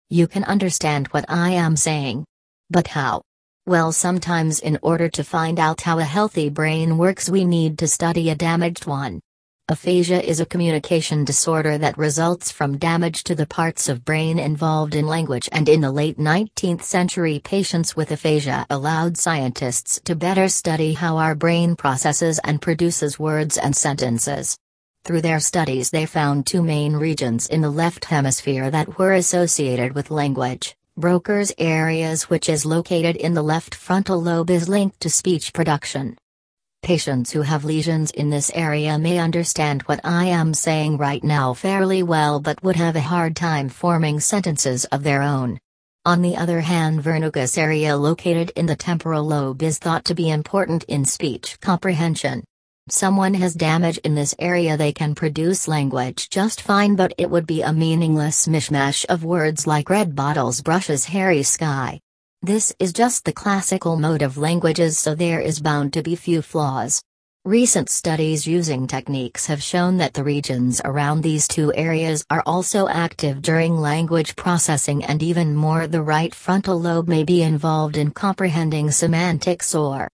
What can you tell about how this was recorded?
At the end of the recording, the last word or group of words are missing.